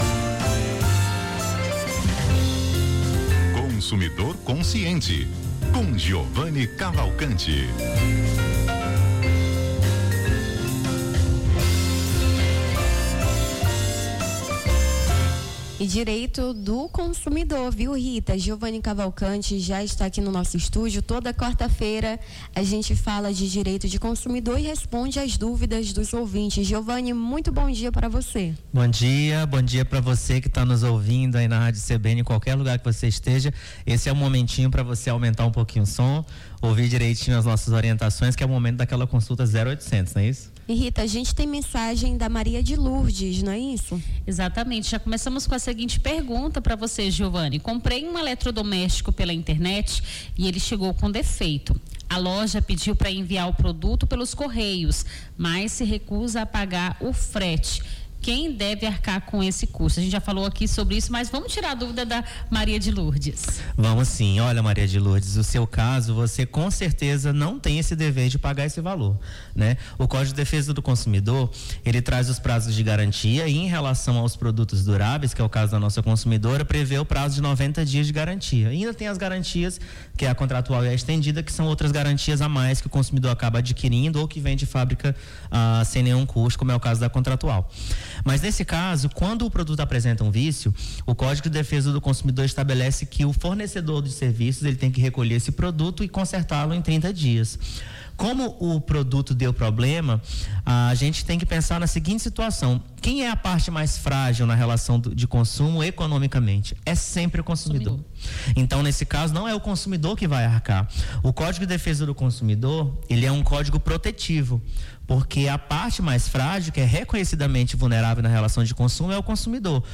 Consumidor Consciente: advogado tira-dúvidas dos ouvintes sobre direito do consumidor